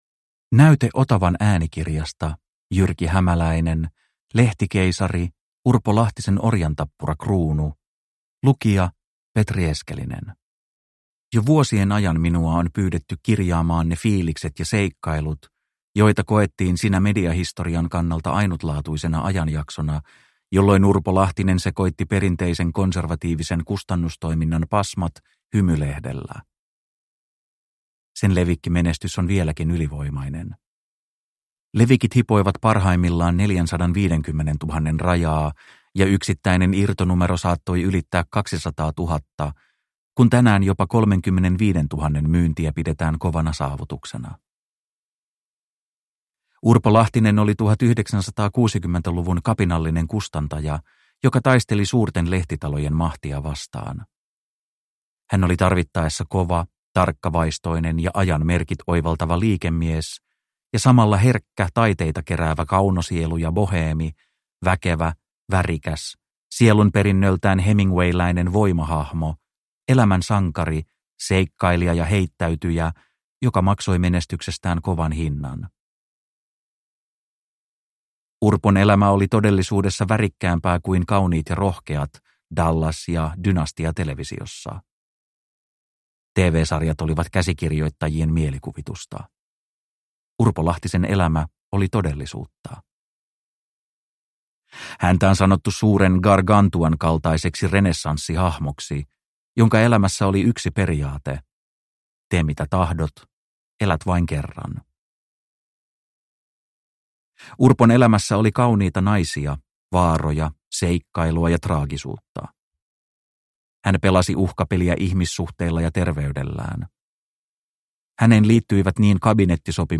Lehtikeisari – Ljudbok – Laddas ner